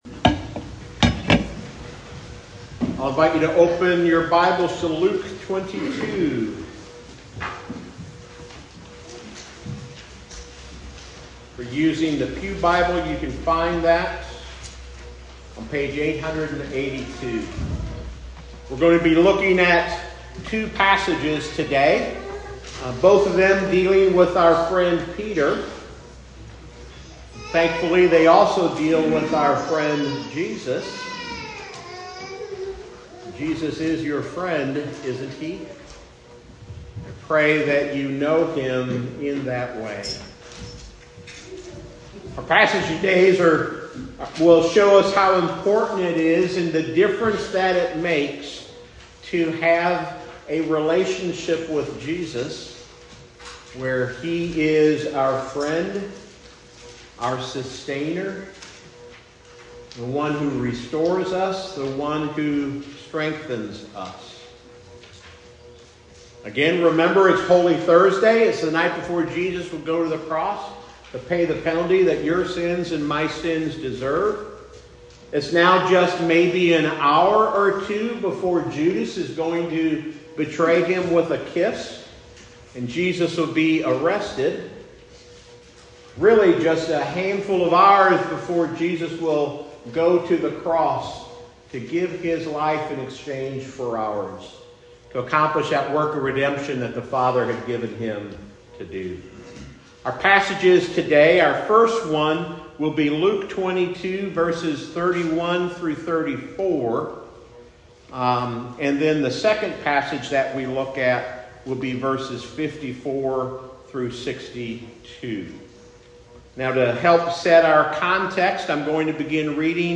Sermon on Luke 22:31-34, 54-62, New Port Presbyterian Church
November 9, 2025, New Port Presbyterian Church